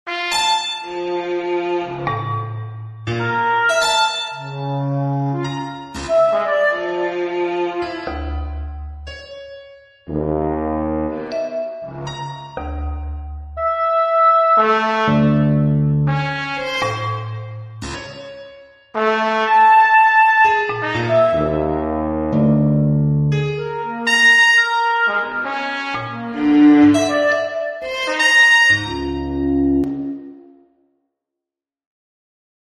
P.S. : comme indiqué ci-dessus, on peut aussi attribuer un timbre différent à chaque note — en respectant toujours la symétrie du diagonnet.
J'ai testé cette idée un an et demi plus tard, le 6 août 2018, en reprenant la même mélodie :
DiagonnetTimbres.mp3